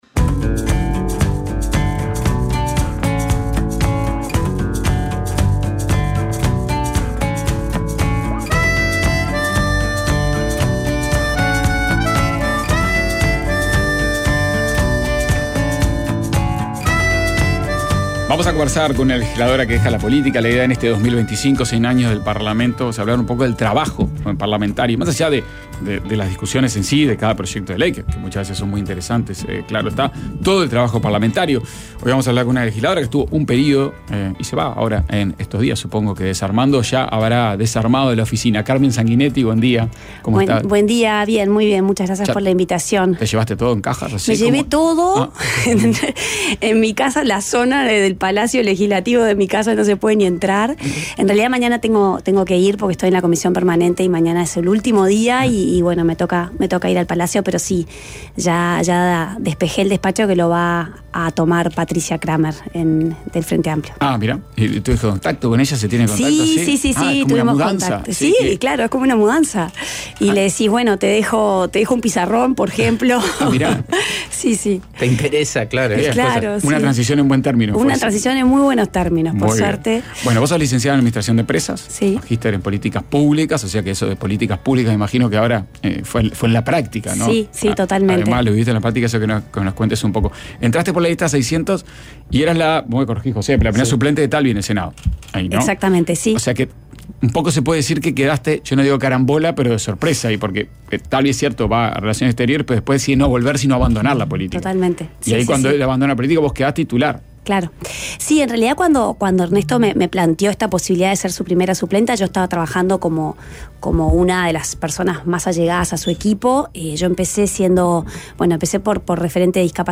Entrevista al ministro de Ganadería, Fernando Mattos, sobre el proyecto Ganadería y Clima y los márgenes de mejora de la productividad de la ganadería uruguaya sobre campo natural.